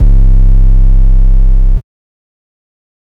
808 (Mafia).wav